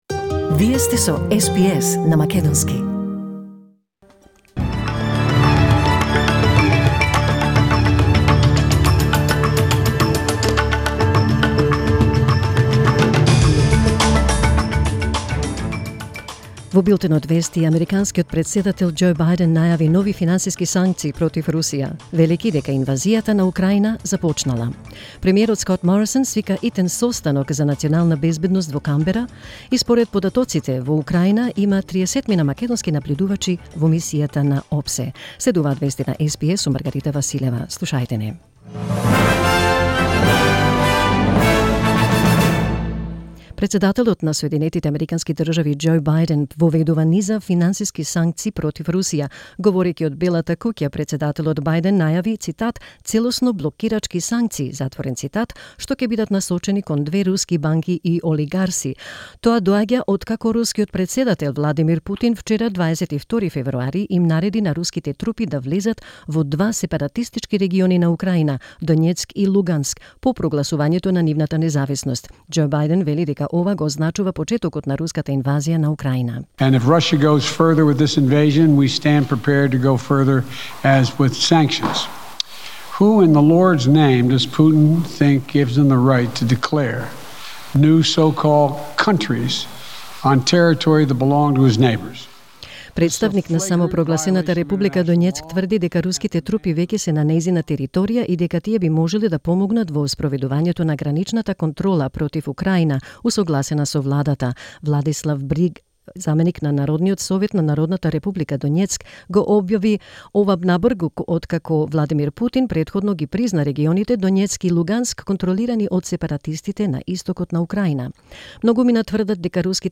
SBS News in Macedonian 23 February 2022